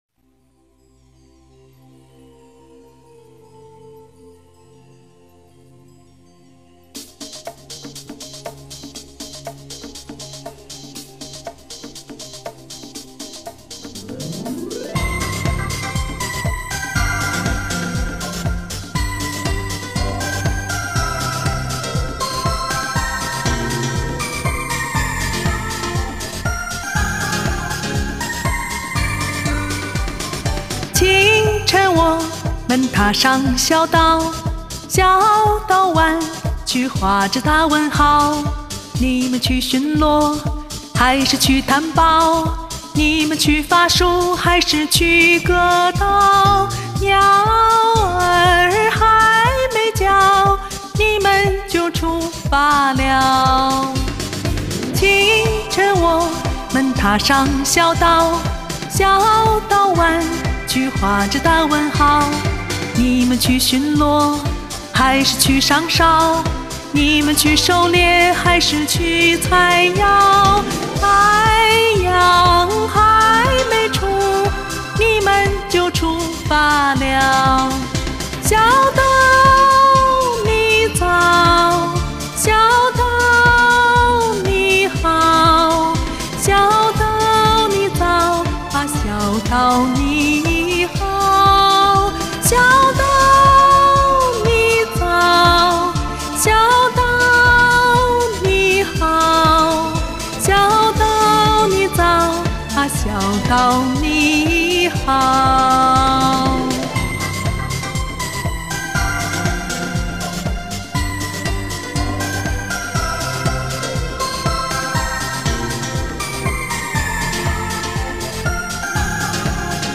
开头的部分没听清伴奏，音不准，但是音色都挺美的，所以一直留着...：P